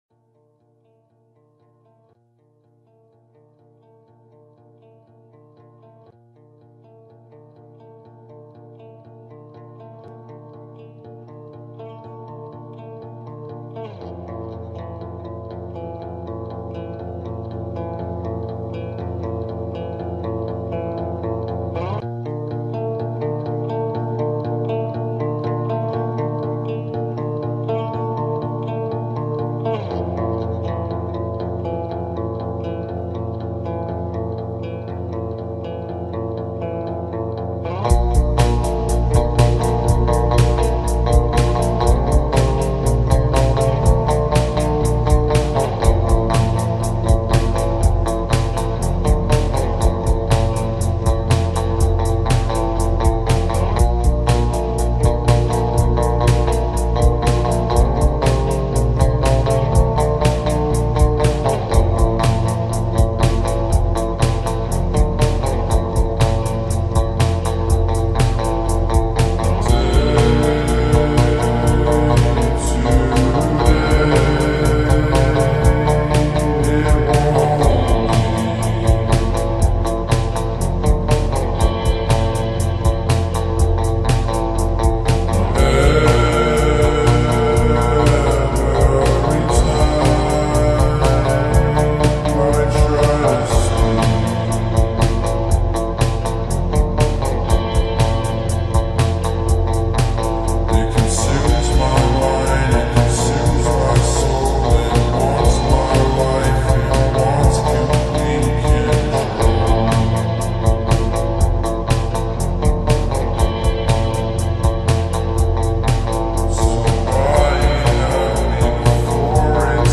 با ریتمی آهسته شده
غمگین